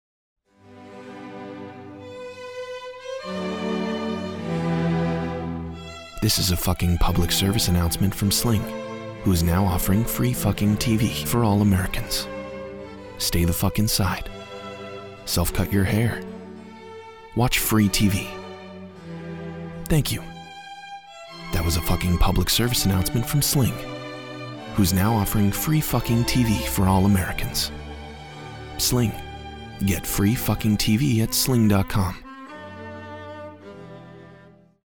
Millennial, Warm, Energetic, American Voice
Chefman - Commercial